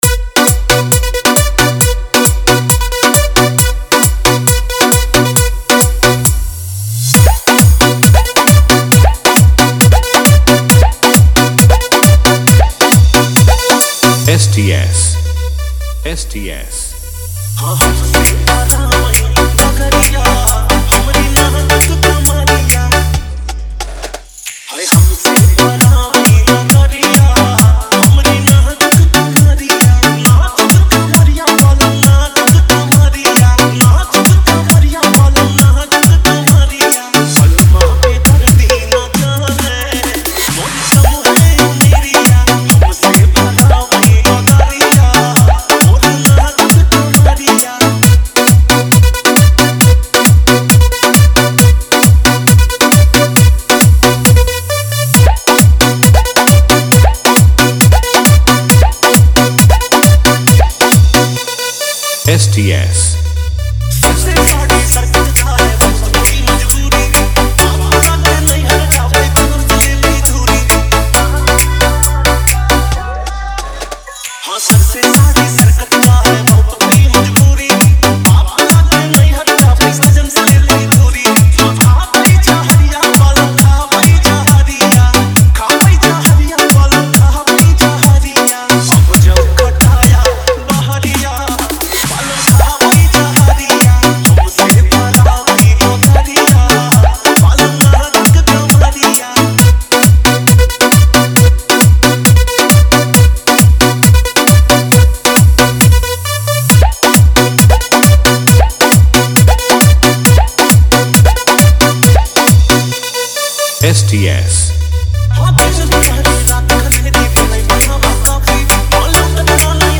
Bhojpuri DJ remix mp3 song